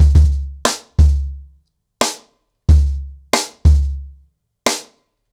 CornerBoy-90BPM.3.wav